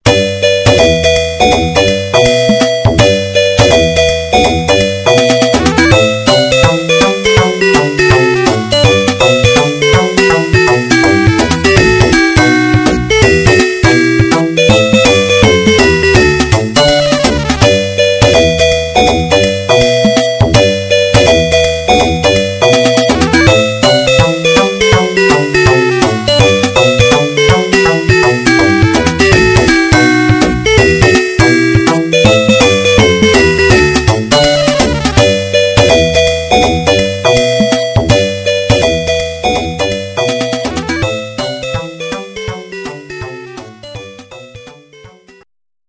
クラシックの名曲をアレンジしたボーナスサウンド！